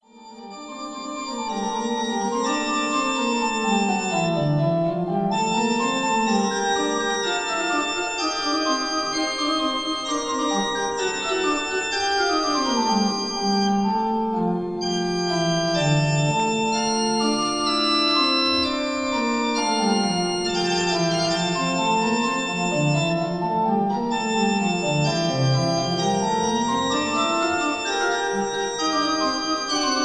Chorale variations:
at the organ of the Marienkirche, Lemgo, Germany